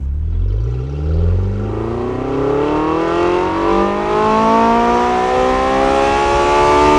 v8_01_accel.wav